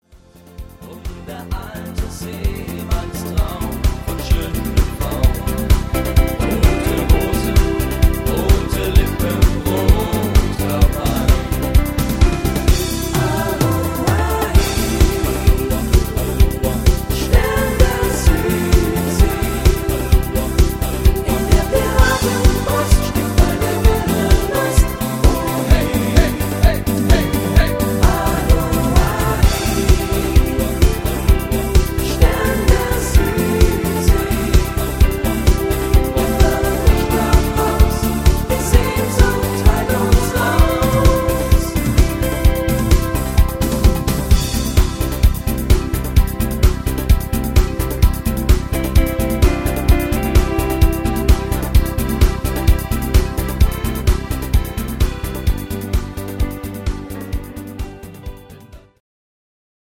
Rhythmus  Latin Discofox
Art  Deutsch, Schlager 2000er